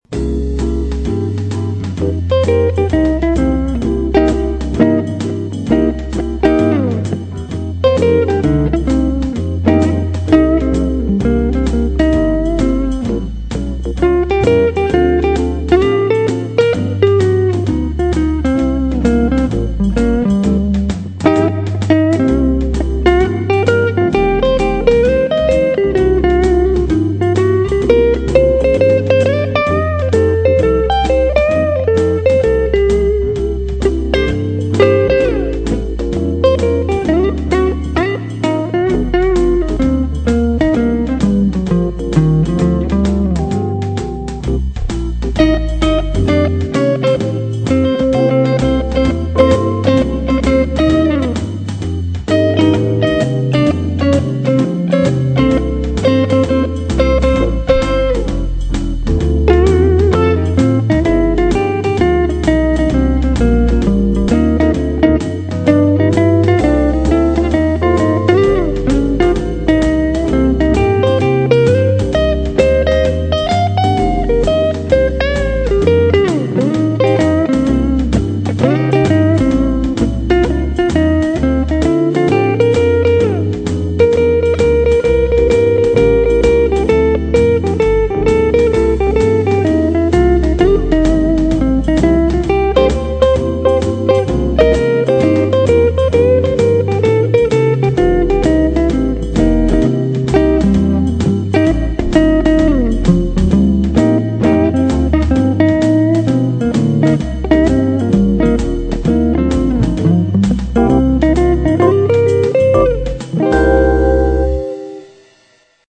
PISTES AUDIO (guitares & basse) + MIDI
Guyatone Jazzy '50 (Jazz . joué sur ma Guyatone demi-caisse)